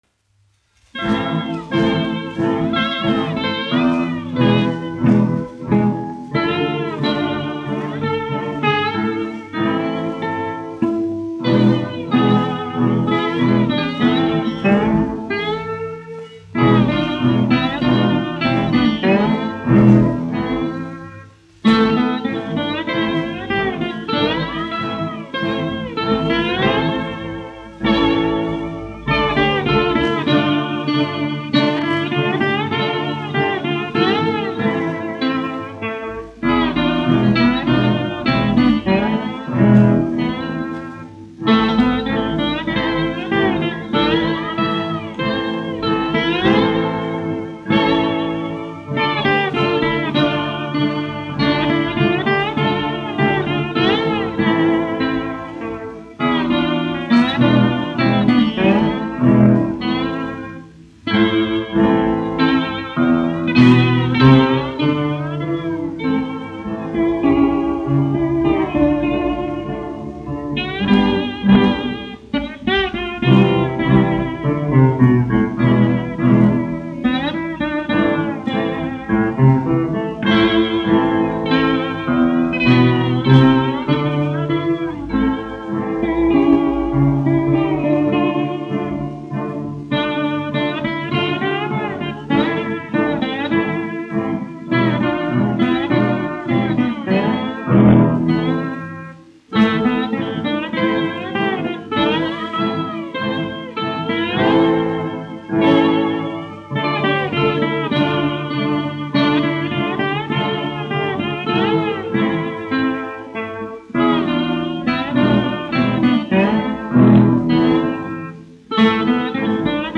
1 skpl. : analogs, 78 apgr/min, mono ; 25 cm
Populārā instrumentālā mūzika
Skaņuplate